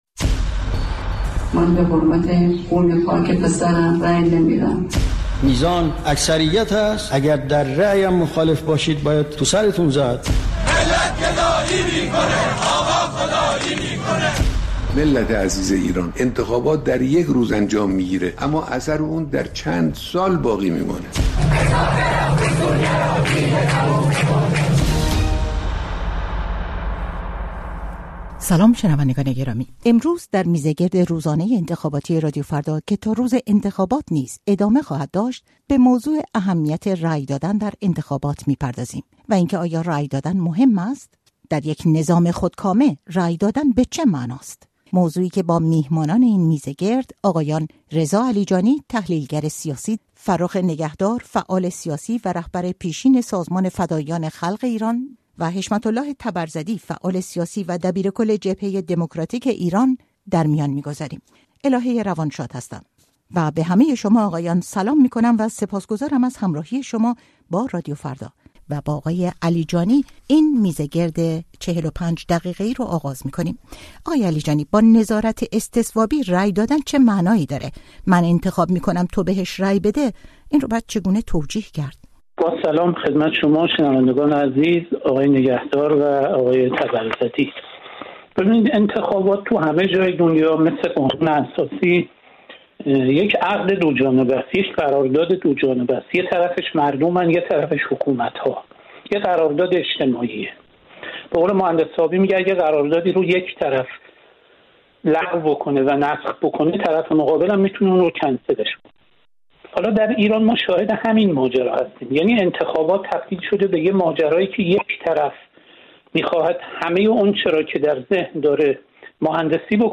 میزگرد ویژه انتخابات: آیا رأی دادن مهم است؟